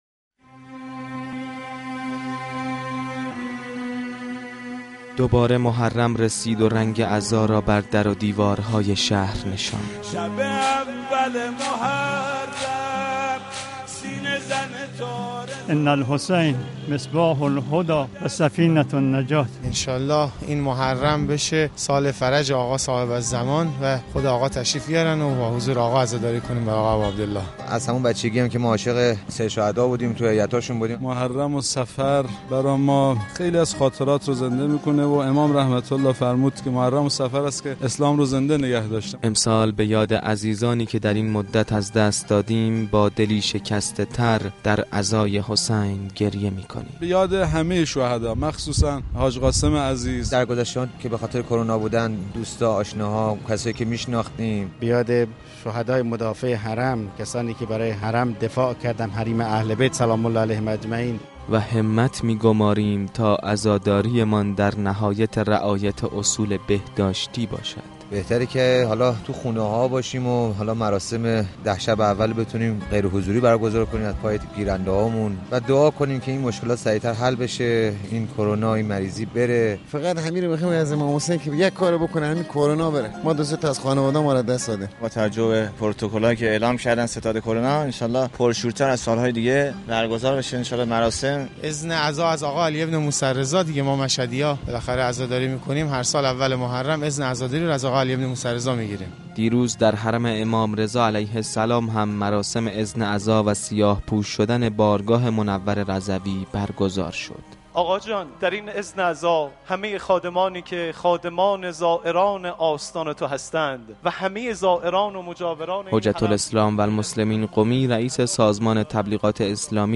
آستان ملائک پاسبان رضوی همزمان با شب اول محرم میزبان مراسم تعویض پرچم سبز گنبد طلای حرم مطهر و آیین اذن عزا بود گزارشی از حال و هوای محرم در شهر امام مهربانی ها